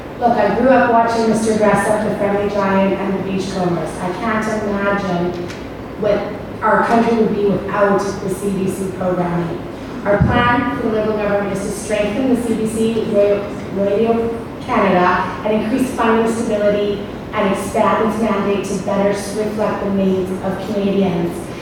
VALLEY VOTES: Algonquin-Renfrew-Pembroke federal candidates debate at Festival Hall L’Equinox a success